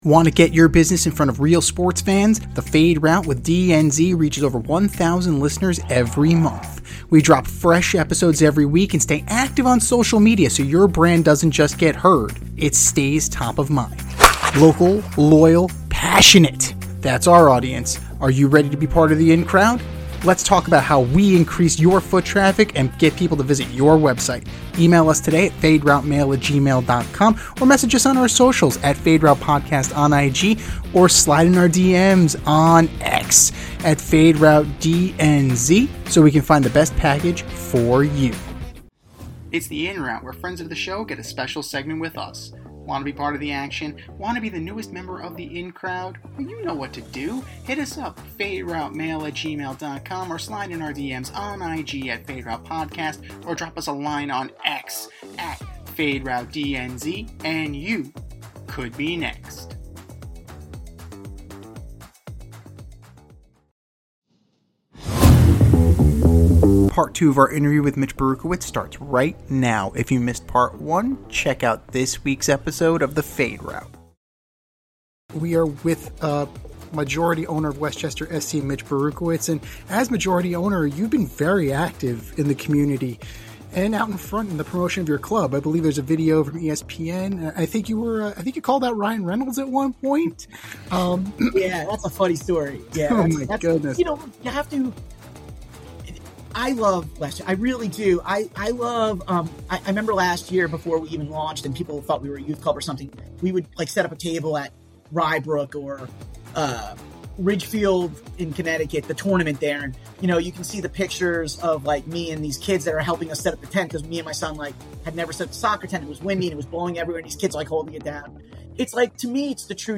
In part 2 of our interview